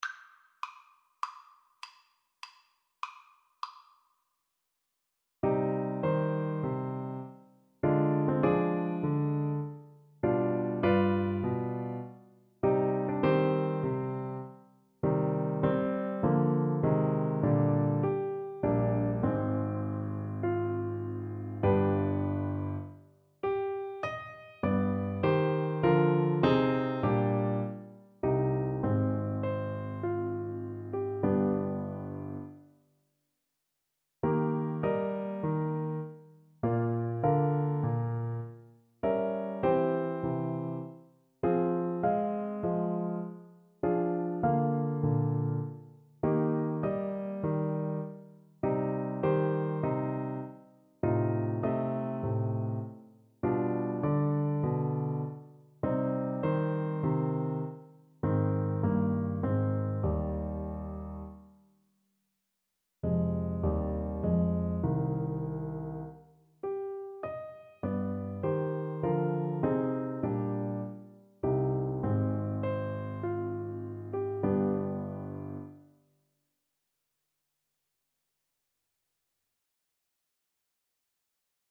Play (or use space bar on your keyboard) Pause Music Playalong - Piano Accompaniment Playalong Band Accompaniment not yet available transpose reset tempo print settings full screen
C minor (Sounding Pitch) A minor (Alto Saxophone in Eb) (View more C minor Music for Saxophone )
Largo =c.100
Classical (View more Classical Saxophone Music)